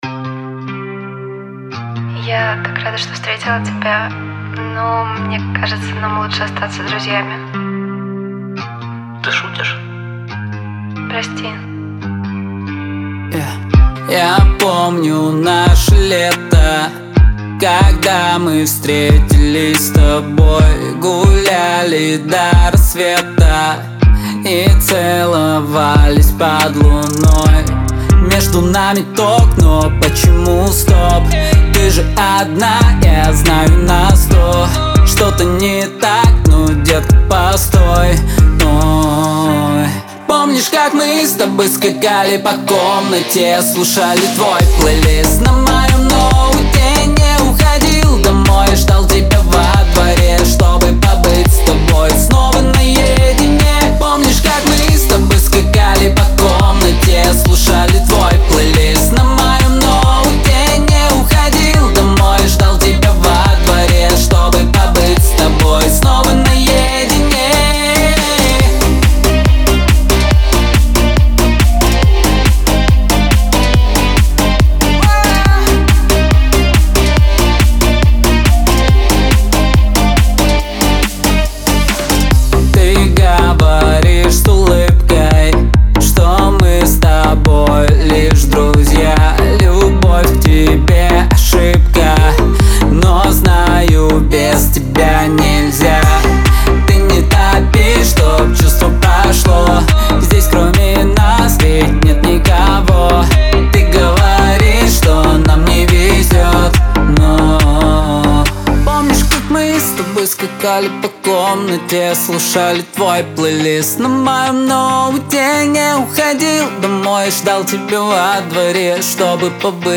это глубокая и меланхоличная композиция в жанре инди-поп.